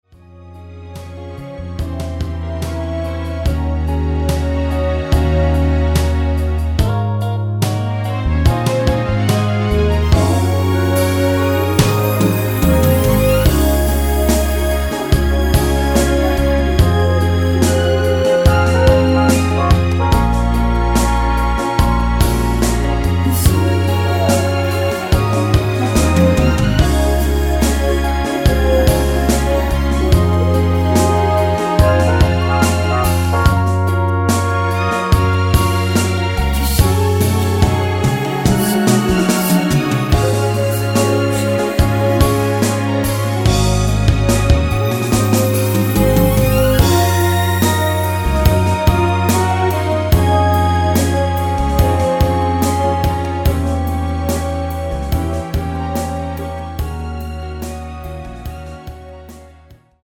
여자키 코러스 포함된 MR 입니다.(미리듣기 참조)
Db
앞부분30초, 뒷부분30초씩 편집해서 올려 드리고 있습니다.